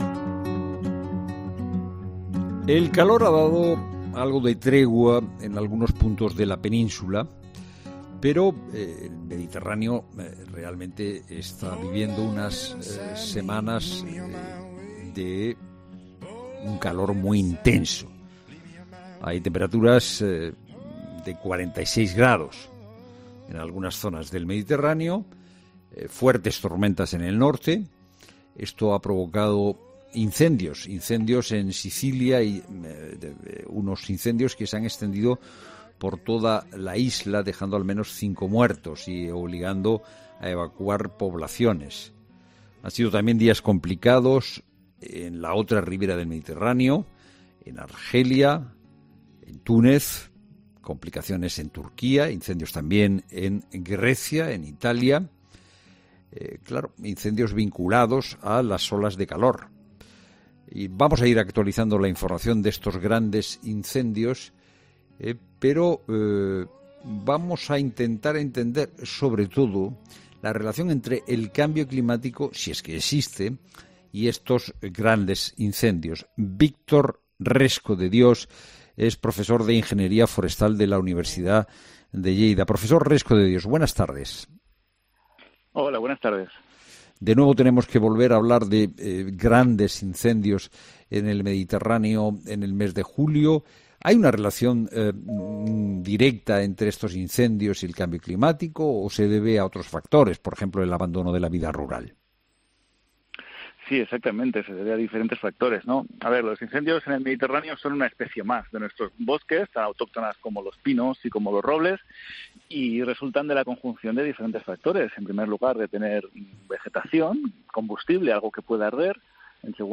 Escucha cómo deberían ser las estruturas para frenar los incendios, según un ingeniero forestal